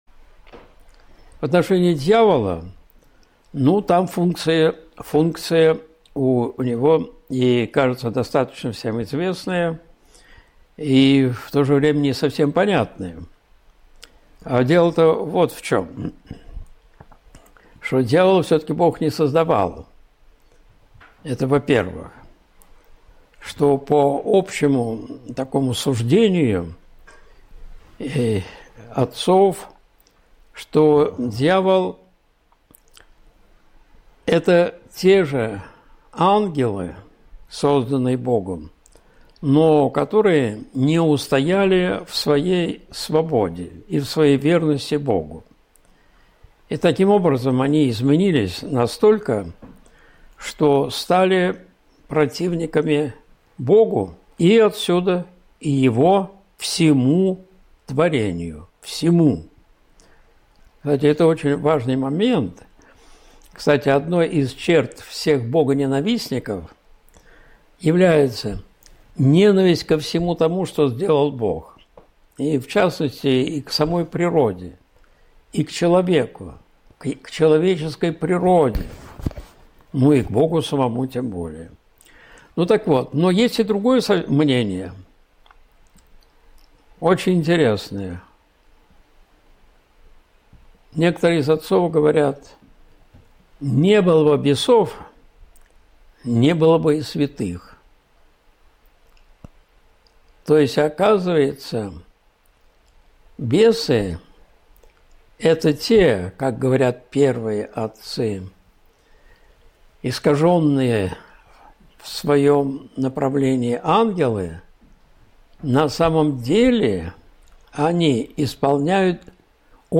Зачем мы нужны Богу? (Прямой эфир, 06.05.2025)
Видеолекции протоиерея Алексея Осипова